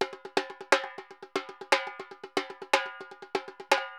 Repique Baion 120_2.wav